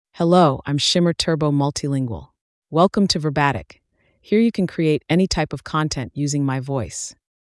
Shimmer Turbo MultilingualFemale English AI voice
Shimmer Turbo Multilingual is a female AI voice for English (United States).
Voice sample
Listen to Shimmer Turbo Multilingual's female English voice.
Shimmer Turbo Multilingual delivers clear pronunciation with authentic United States English intonation, making your content sound professionally produced.